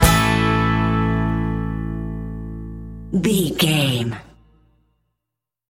Ionian/Major
Fast
drums
electric guitar
bass guitar
banjo
acoustic guitar